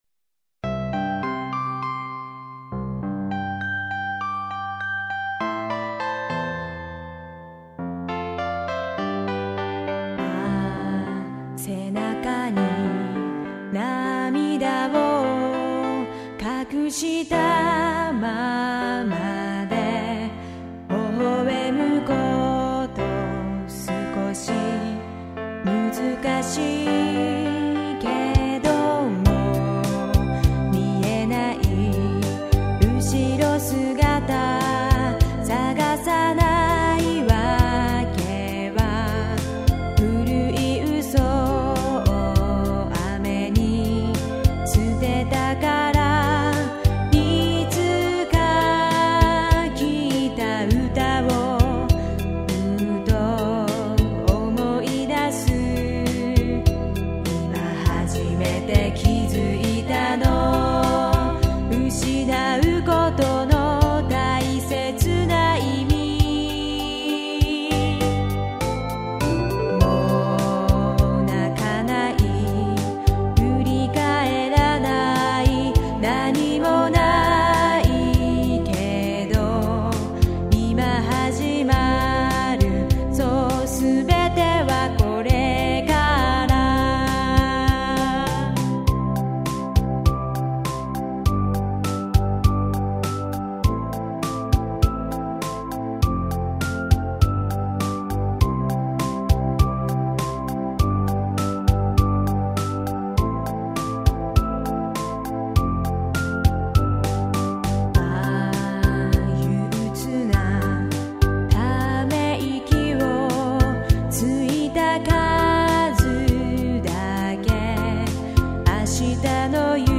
レコーディングで、レコード店のスタジオを借りて録音し、CDを作成していた。